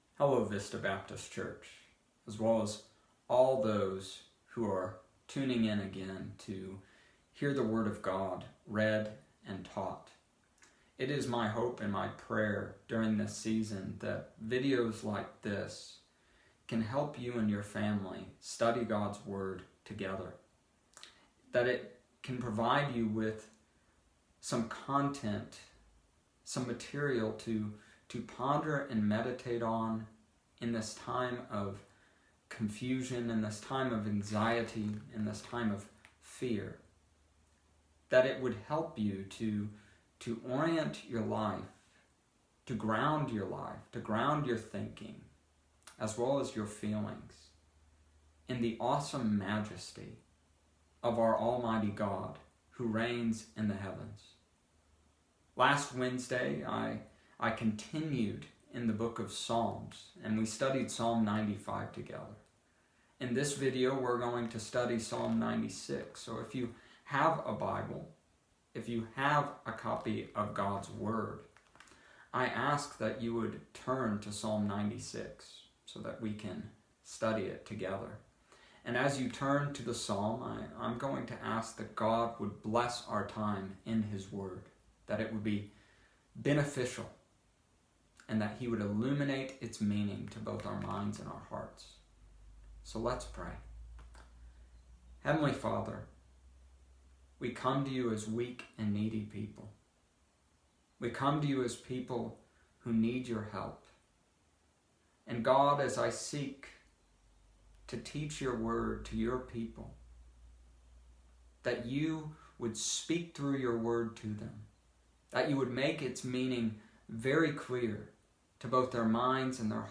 Wednesday Devotion: Psalm 96